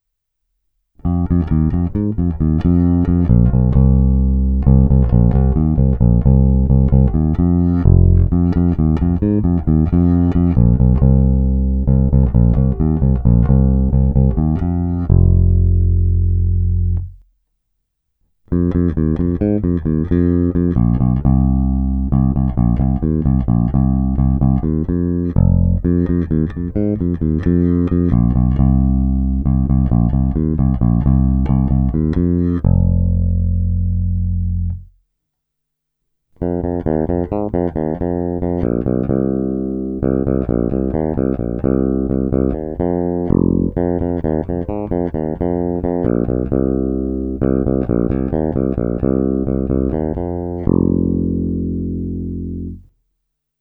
Udělal jsem jen jednu sadu ukázek v pořadí krkový snímač – oba snímače – kobylkový snímač.
Squier stock pickups